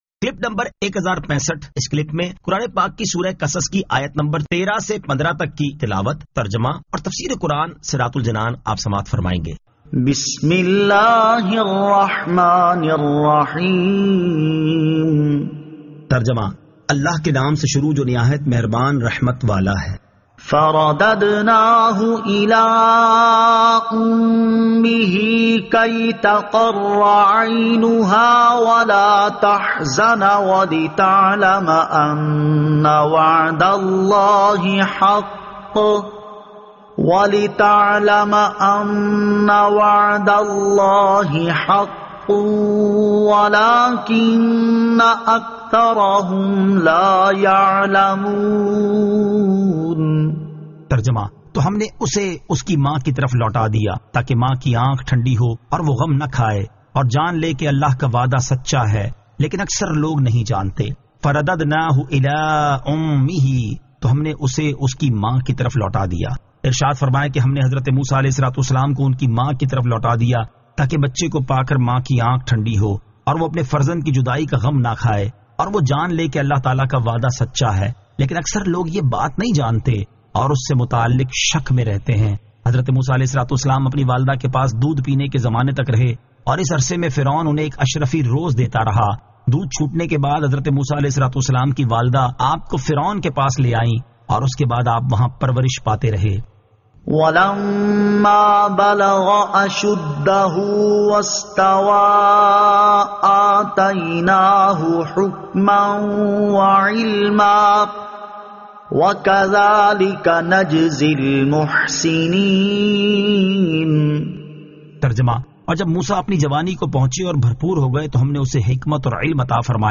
Surah Al-Qasas 13 To 15 Tilawat , Tarjama , Tafseer